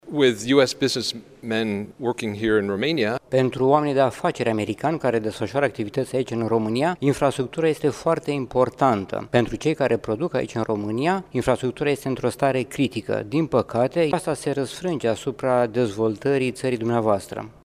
Infrastructura din România este în stare critică şi din păcate deficienţele acesteia se răsfrâng asupra dezvoltării ţării, a arătat astăzi într-o conferinţă susţinută la Iaşi, Excelenţa Sa Hans Klemm, Ambasadorul Statelor Unite la Bucureşti.
Ambasadorul este prezent la Unviersitatea „Alexandru Ioan Cuza” din Iaşi la o conferinţă care tratează teme legate de dezvoltare internaţională şi democratizare.